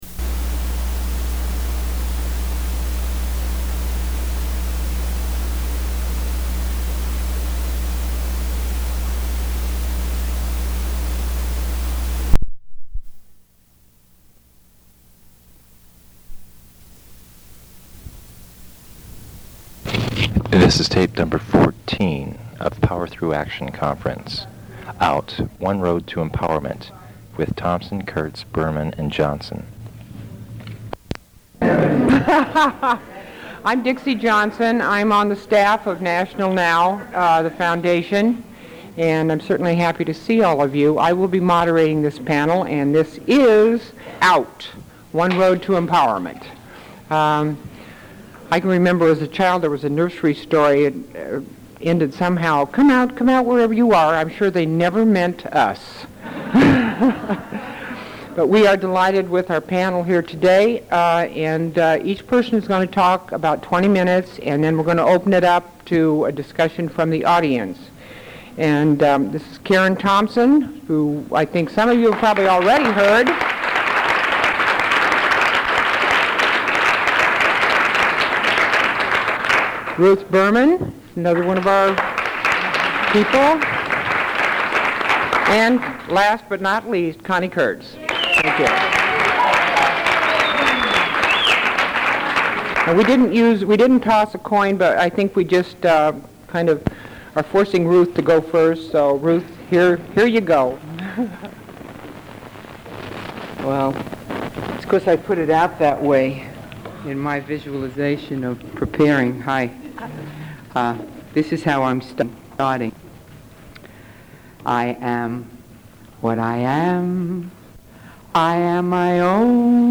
Interview with Bay Area Reporter